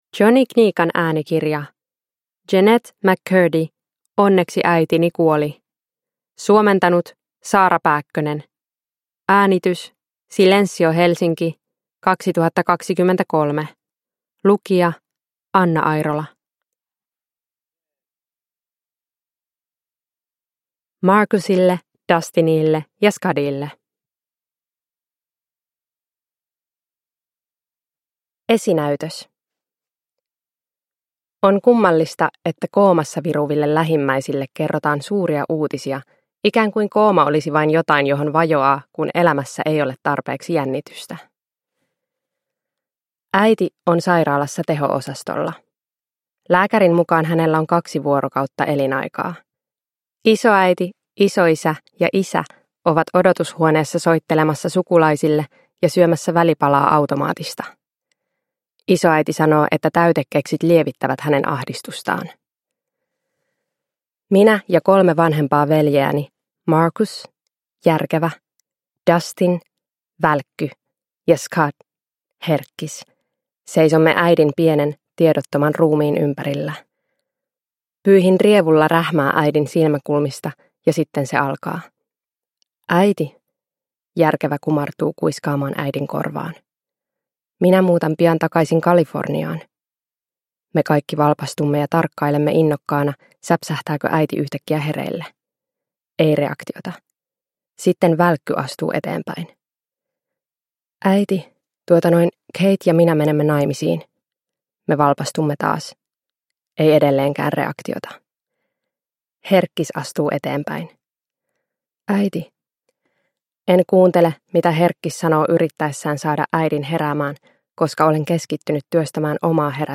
Onneksi äitini kuoli – Ljudbok – Laddas ner